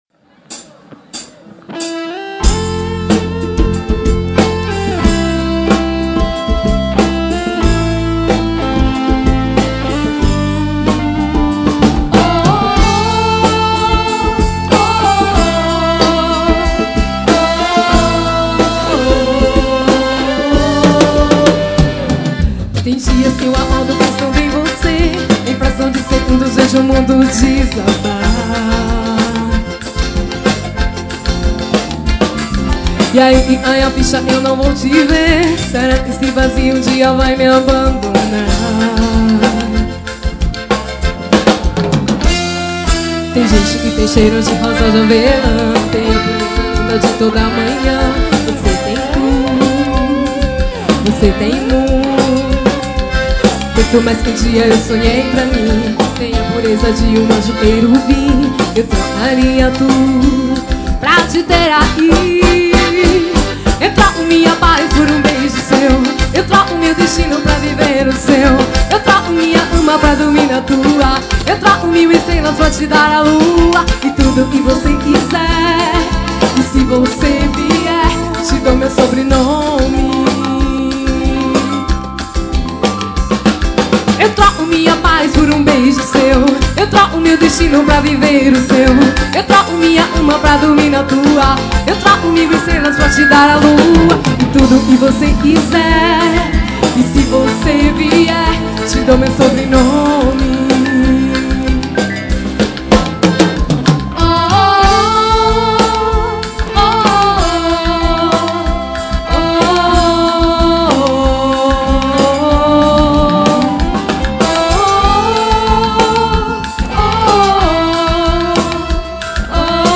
Show ao vivo